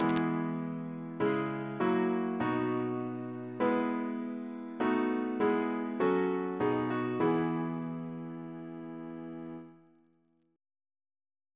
Single chant in E Composer: John T. Frye (1812-1887) Reference psalters: ACB: 287